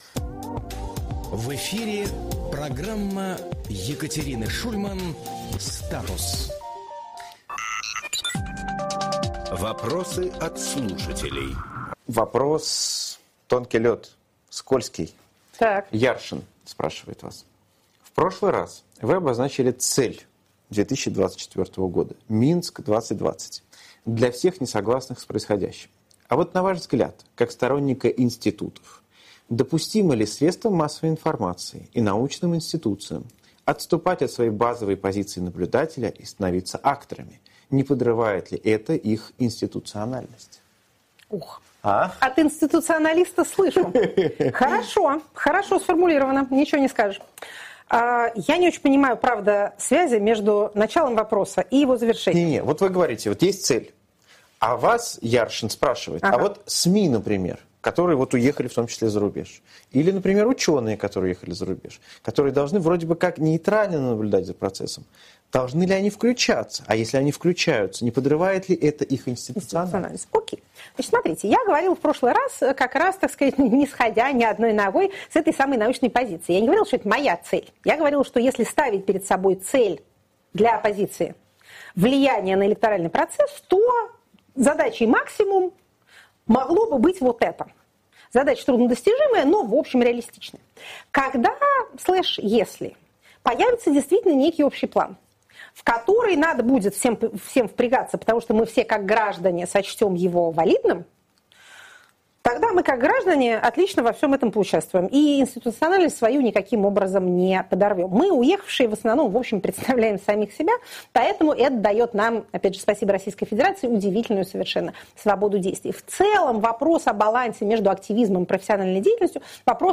Екатерина Шульманполитолог
Фрагмент эфира от 3 октября.